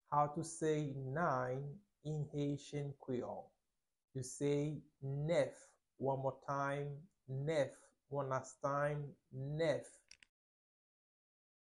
Pronunciation:
12.How-to-say-Nine-in-Haitian-Creole-–-Nef-pronunciation-.mp3